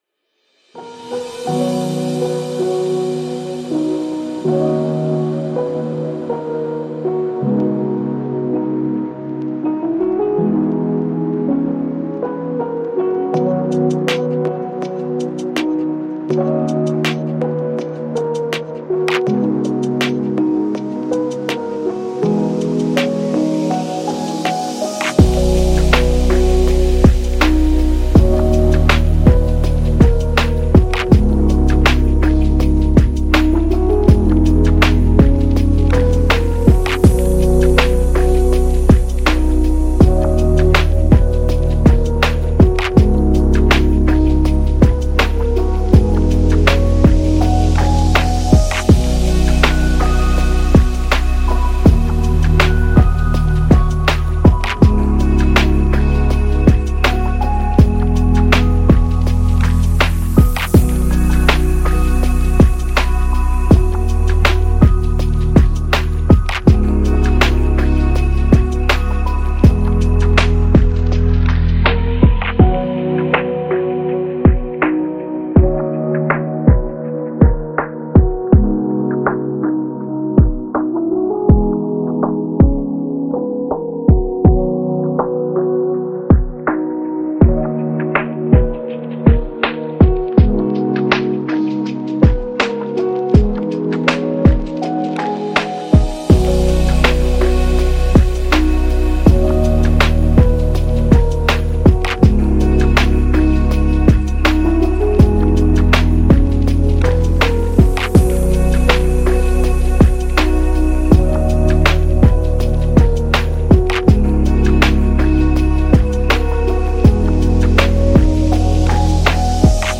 Relax Lofi